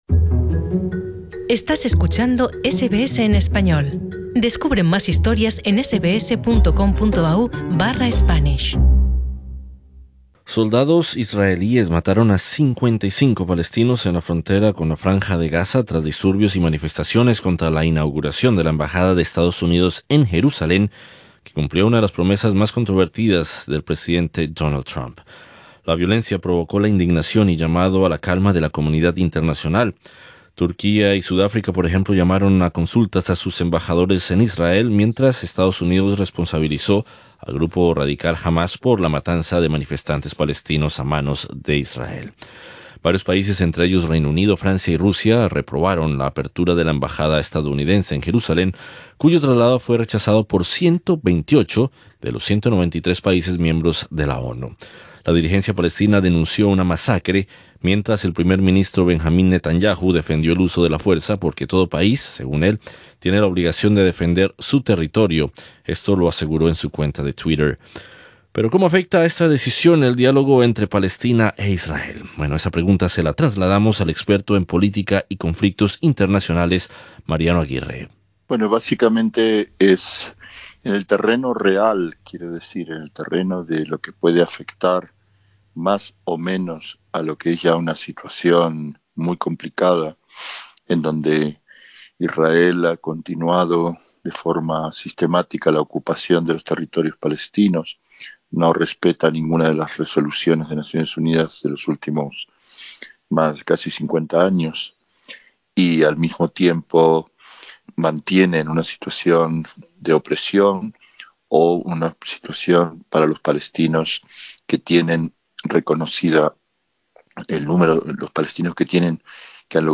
Entrevista al experto en política y conflictos internacionales